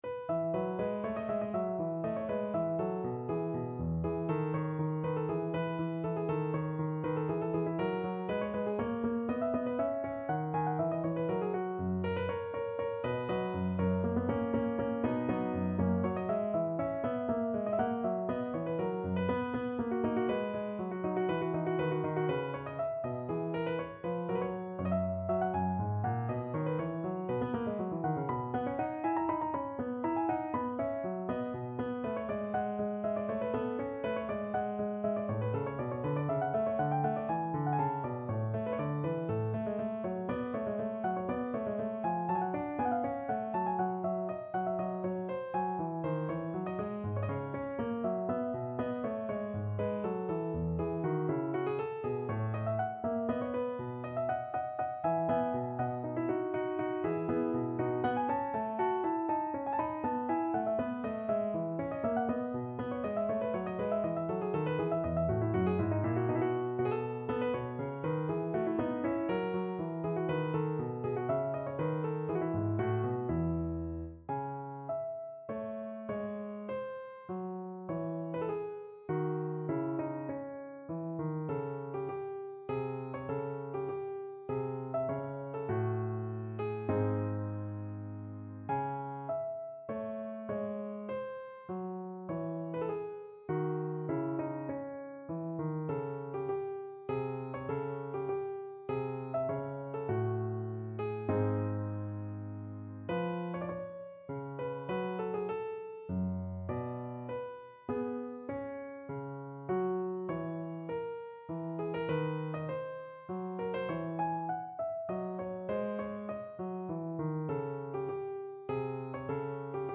Free Sheet music for Piano
No parts available for this pieces as it is for solo piano.
2/4 (View more 2/4 Music)
Vivace (View more music marked Vivace)
E major (Sounding Pitch) (View more E major Music for Piano )
Classical (View more Classical Piano Music)